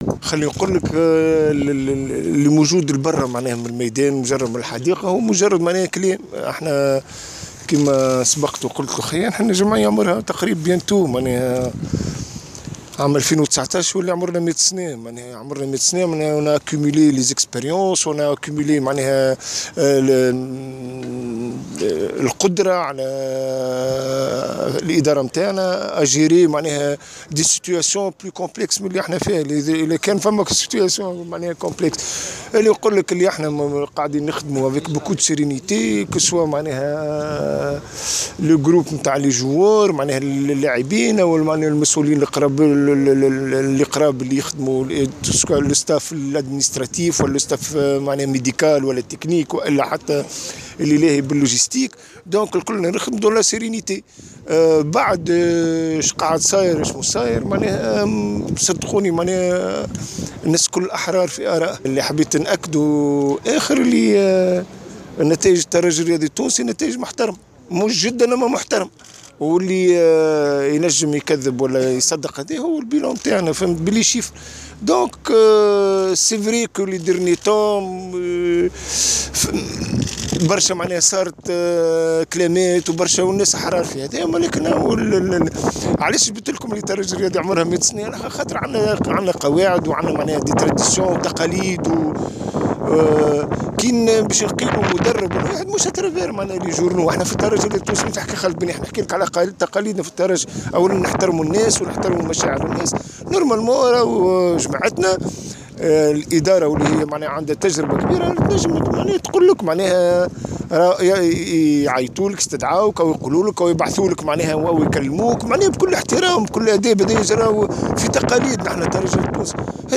خالد بن يحي :مدرب الترجي الرياضي